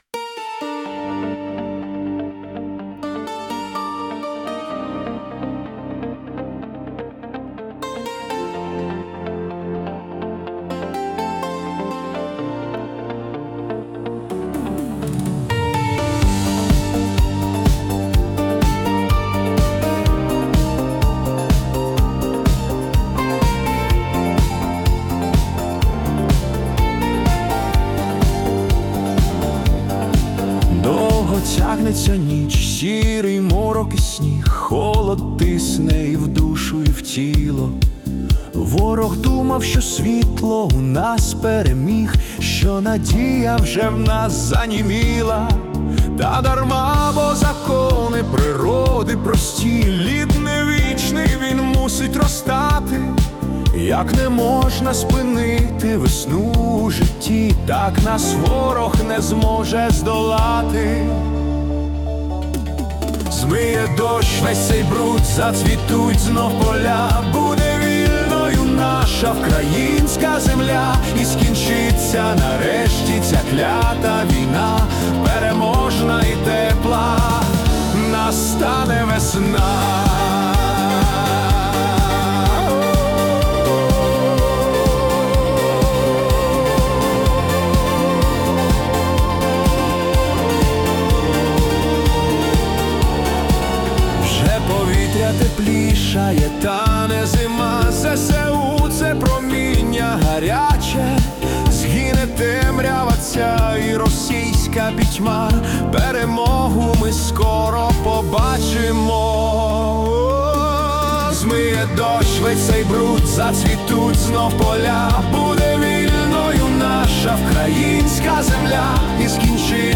🎵 Жанр: Pop-Folk / Italo-Disco